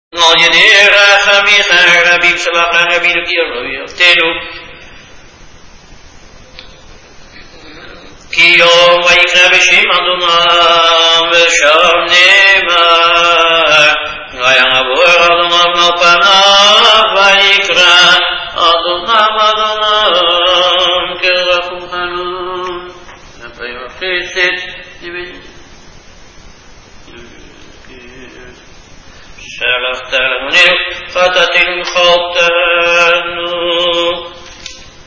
Chazzan